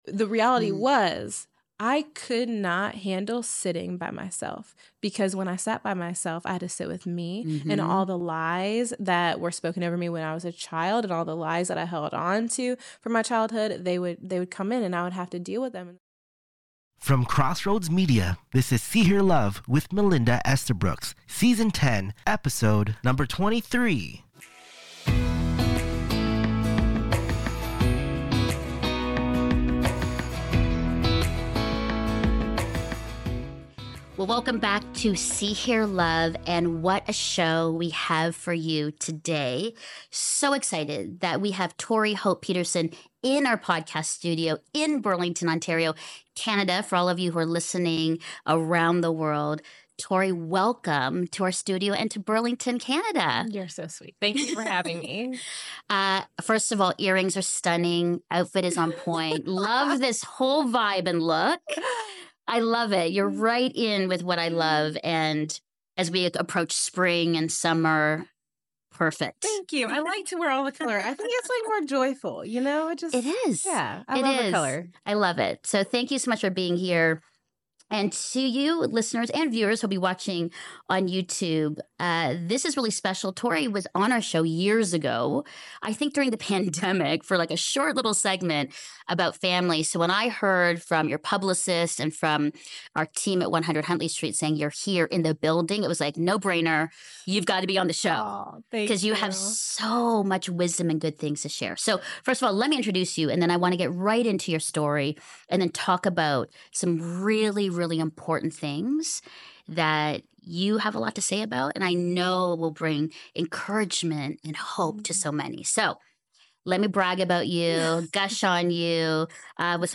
One on One Interview